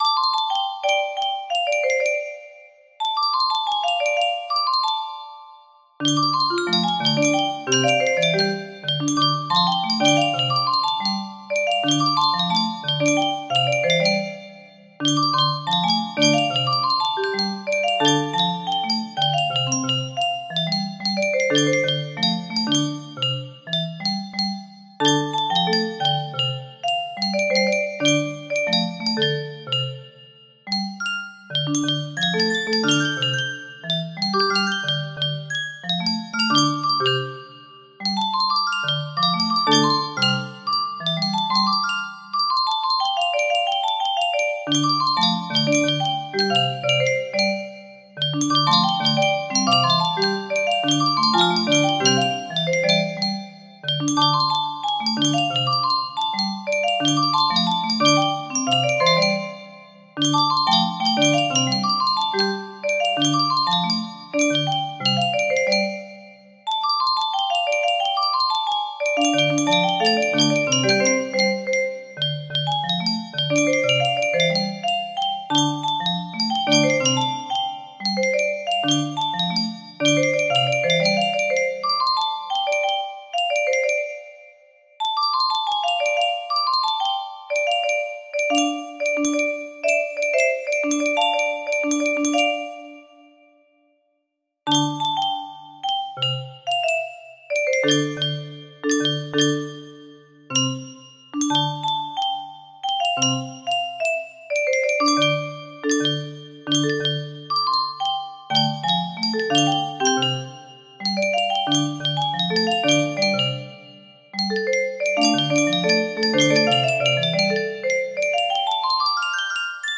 Full range 60
Adjusted the tempo for music box, no reds, Enjoy!